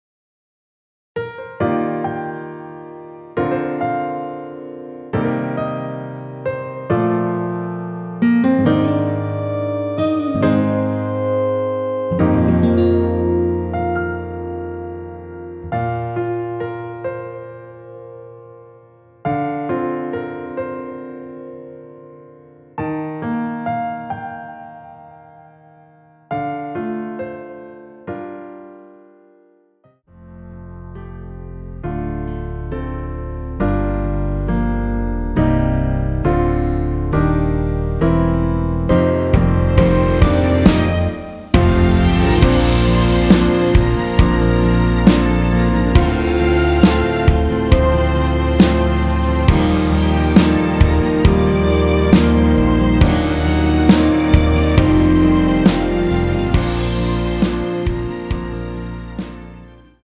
원키에서(-2)내린 (1절+후렴)으로 진행되는 MR입니다.
Bb
앞부분30초, 뒷부분30초씩 편집해서 올려 드리고 있습니다.
중간에 음이 끈어지고 다시 나오는 이유는